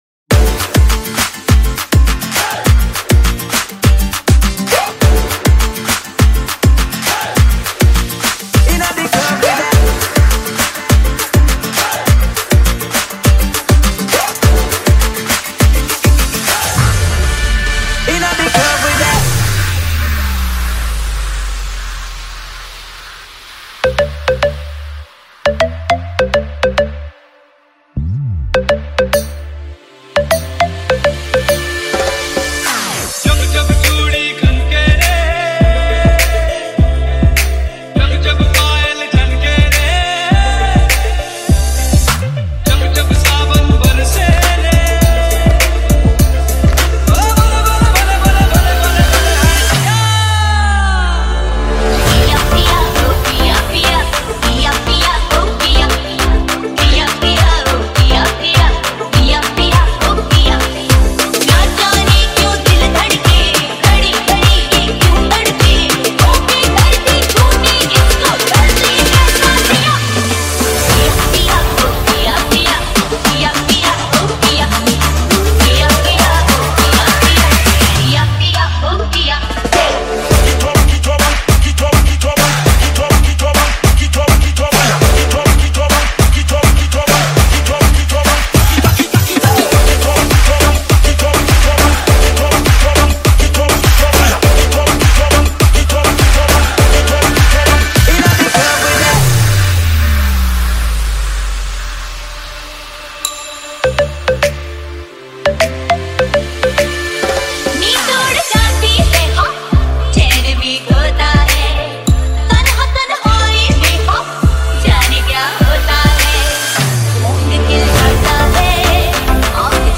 High quality Sri Lankan remix MP3 (2.9).